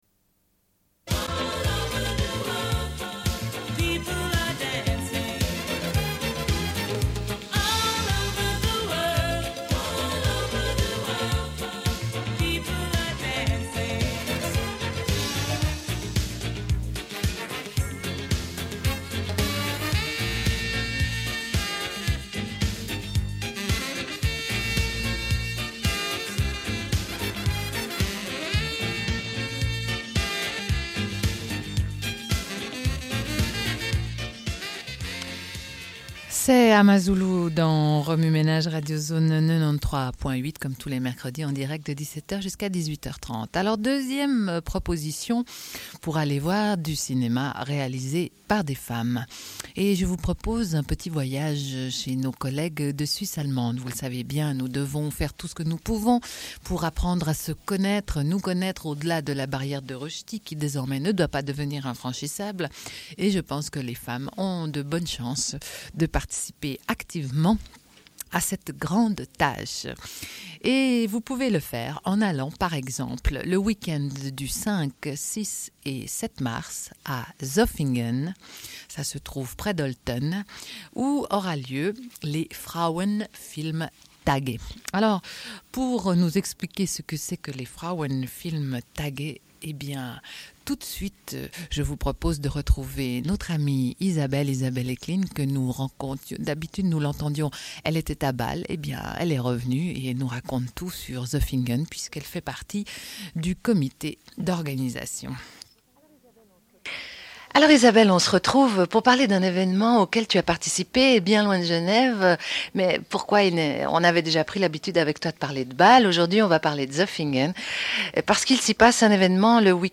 Une cassette audio, face B28:57